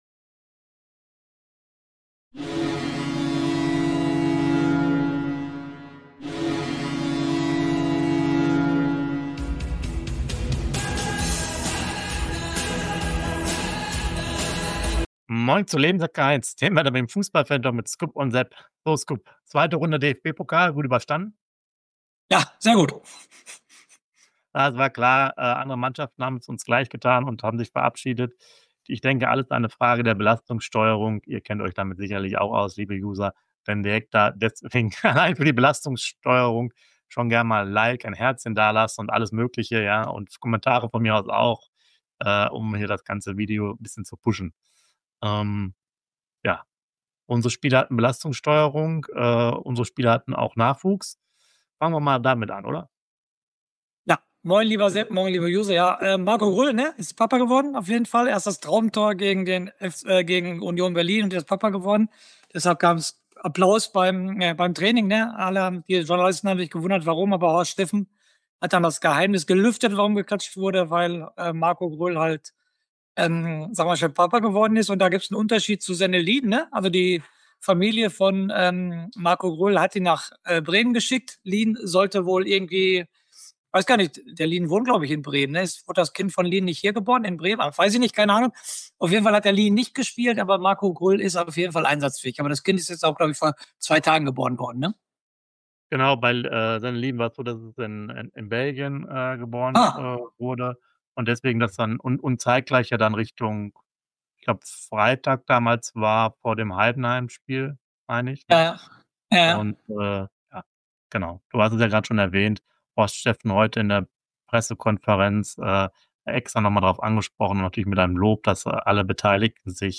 FANTALK!!! Hier gibt es Infos, News und heiße Diskussionen vor, zwischen und nach den Spieltagen zu unserem Verein.